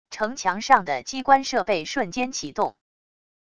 城墙上的机关设备瞬间启动wav音频